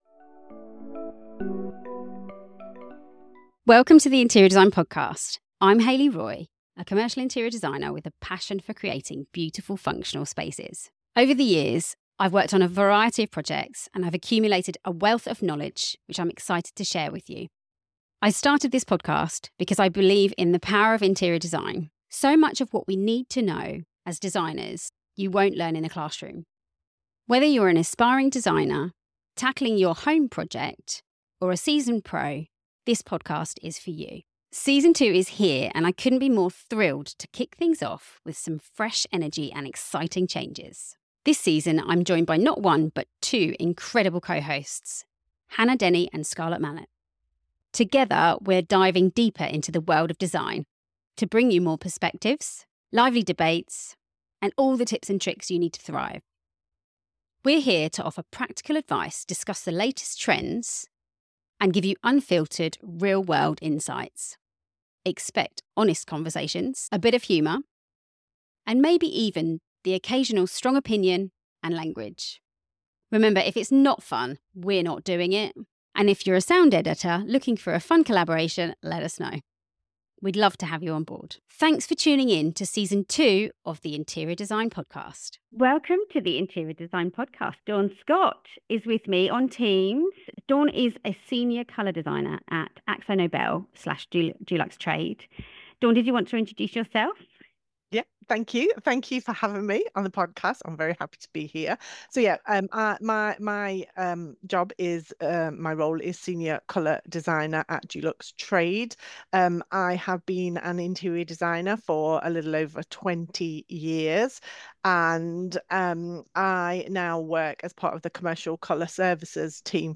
We’re here to keep it real, with passionate discussions, bold opinions, and yes, the occasional swear word or cheeky innuendo!